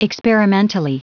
Prononciation du mot experimentally en anglais (fichier audio)
Prononciation du mot : experimentally